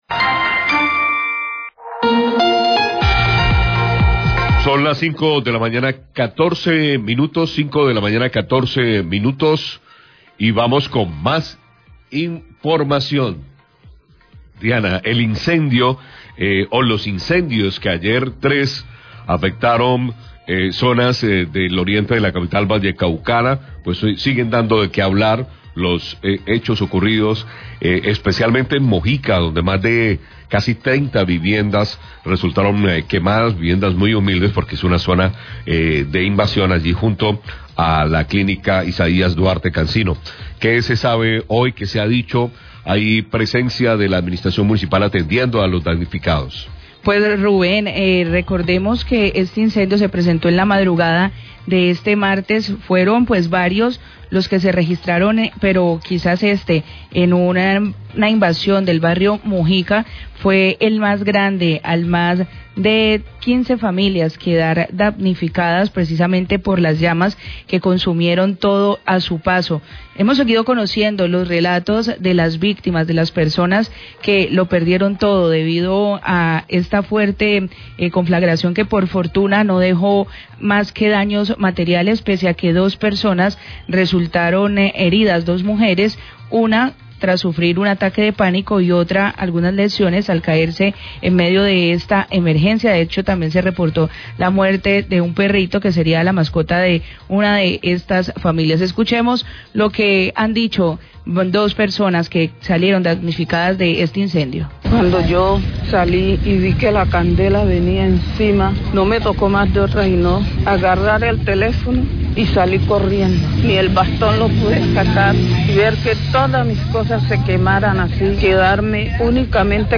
Radio
Hablaron las personas afectadas por el incendio en el barrio Mojica. Desde la secretaría de Gestión de Riesgo se entregó un balanca y evaluación de las necesidades de las familias afectadas.